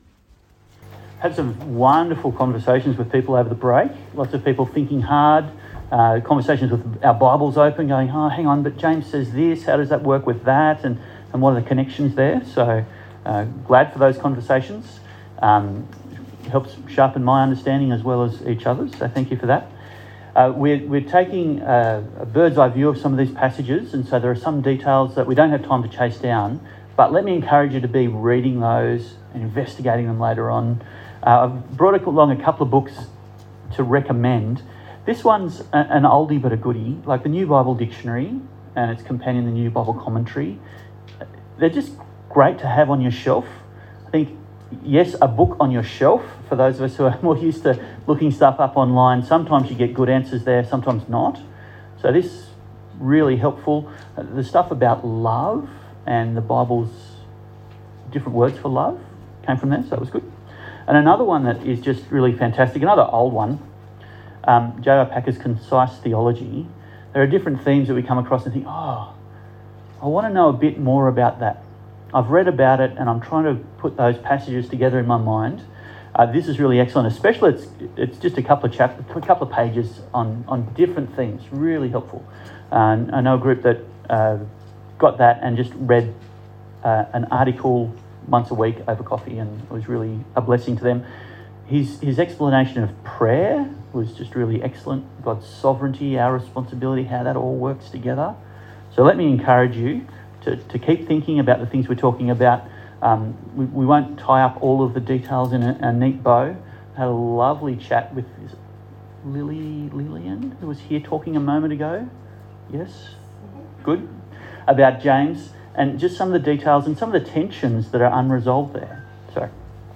Love Your Church Passage: Hebrews 12:18-29 Service Type: Church Camp Talk